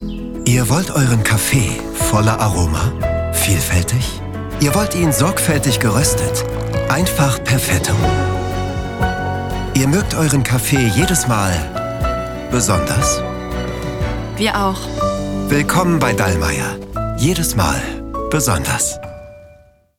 Werbung - Flexifresh V1 (Demo) cool, hart